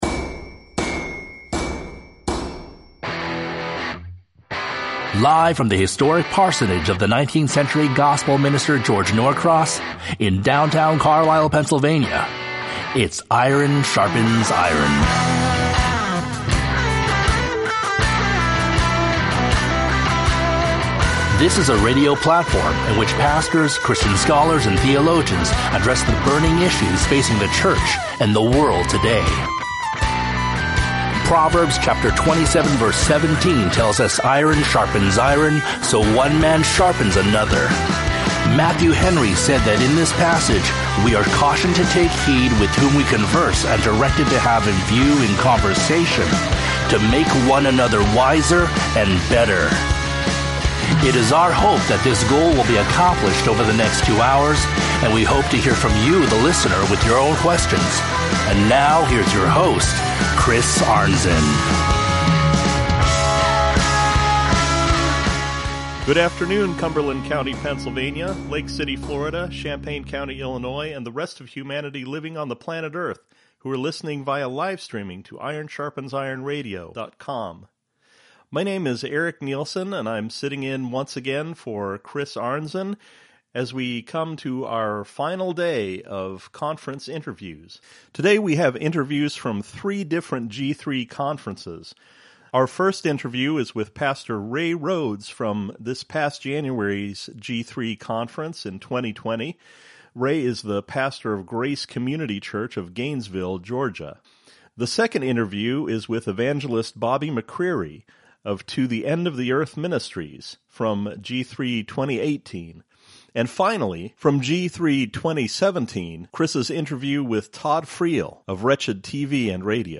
Featuring Interviews from the 2020 G3 Conference with